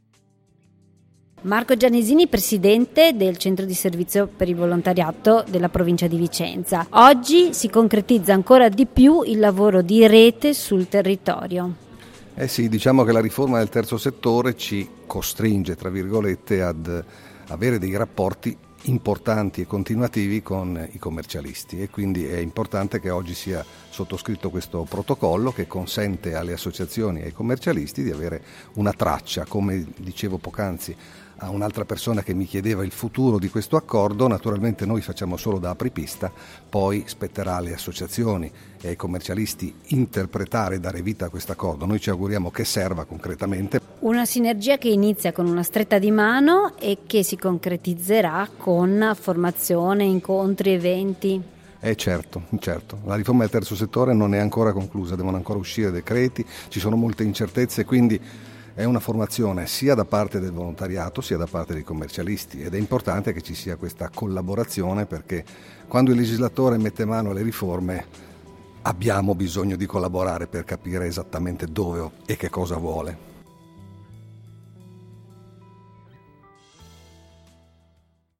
Alla sottoscrizione del Protocollo d’Intesa tra Commercialisti ed Esperti Contabili di Vicenza e Centro di Servizio per il Volontariato della provincia di Vicenza, che sancisce l’inizio di una concreta collaborazione per migliorare la formazione e i servizi offerti alle associazioni, abbiamo raccolto le dichiarazioni dei relatori e ve le proporremo durante le prossime settimane.